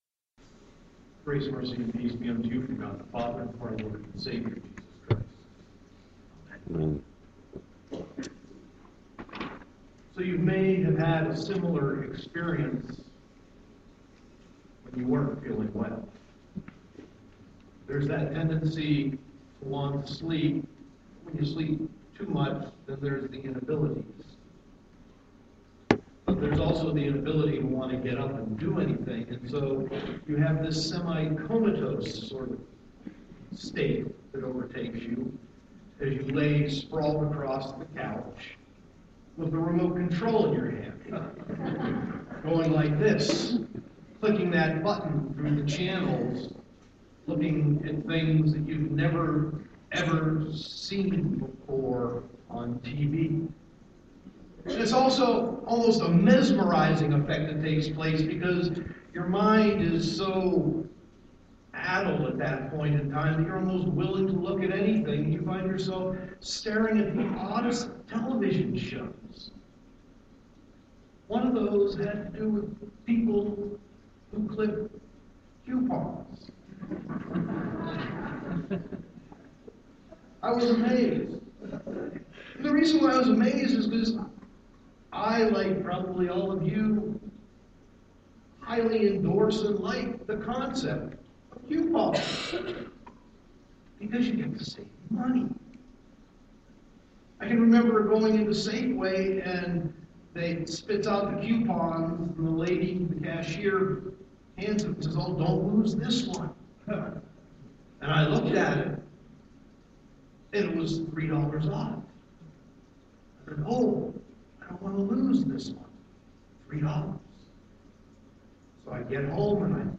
Sermon 2.21.2016